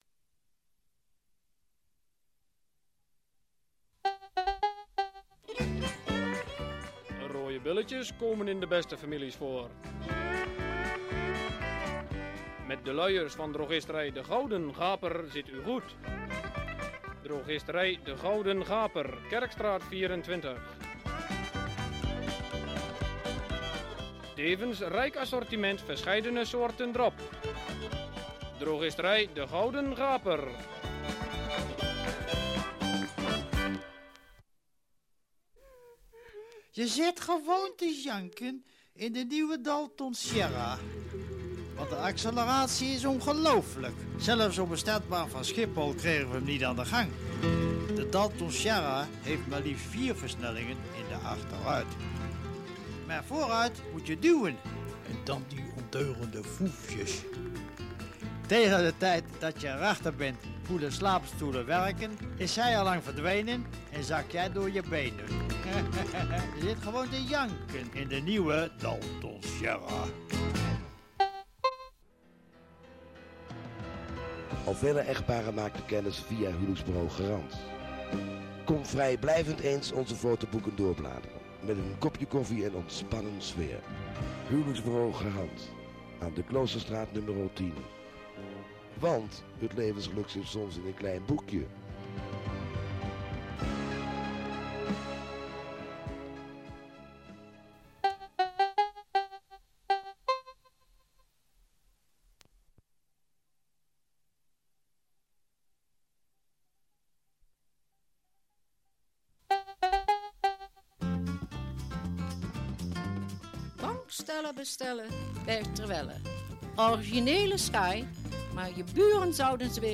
Radio de Zwarte Roos – Reclameblokjes
Reclameblokjes-Zwarte-Roos.mp3